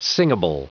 Prononciation du mot singable en anglais (fichier audio)
Prononciation du mot : singable